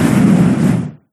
Flamethrower End.wav